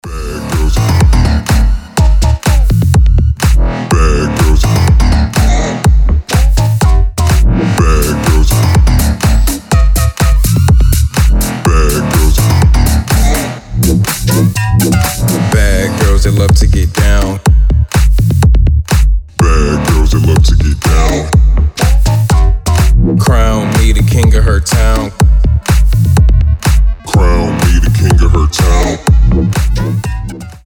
• Качество: 320, Stereo
громкие
Electronic
EDM
Bass House
качающие
electro house
низкий мужской голос